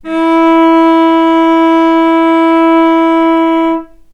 vc-E4-mf.AIF